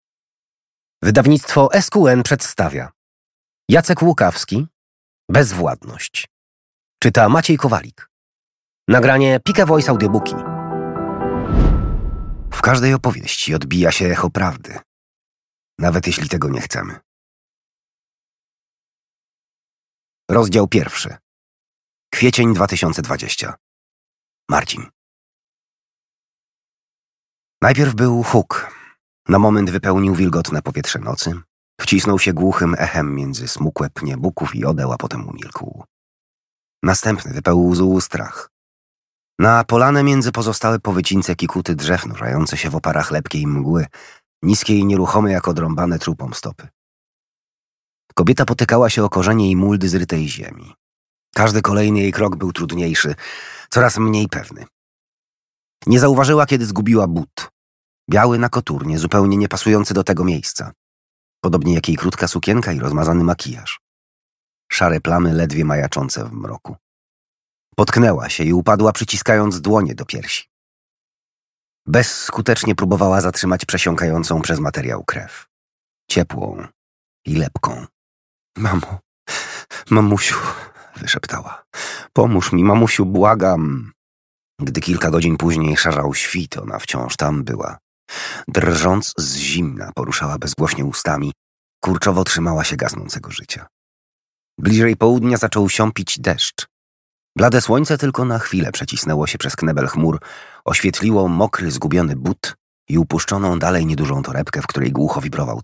Przeczytaj i posłuchaj fragmentów Pobierz fragment zamknij × Darmowy fragment "(audiobook) Bezwładność" Dostępność: Audiobooka odsłuchasz w aplikacji PulpUp.